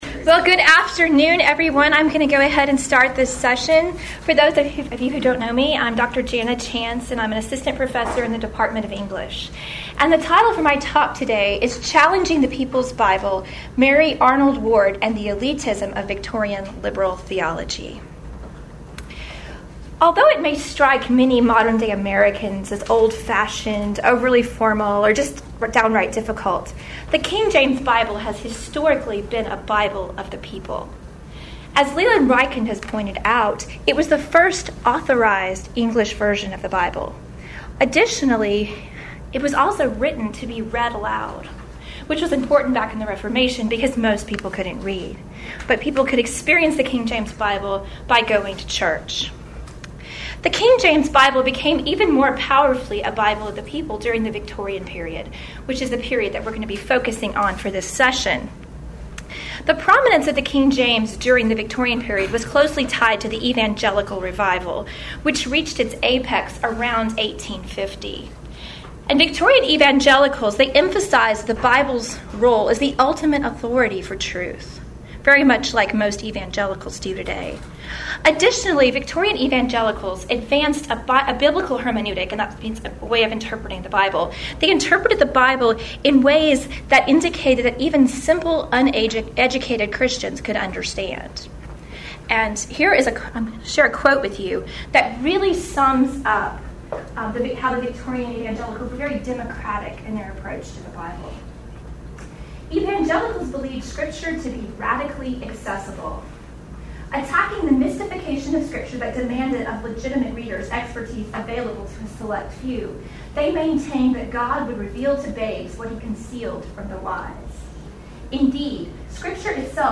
KJV400 Festival
Address: Challenging the People's Bible: Mary Arnold Ward and the Elitism of Victorian Liberal Theology